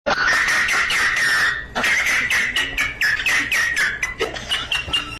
Dog Laughing At You - Botón de Efecto Sonoro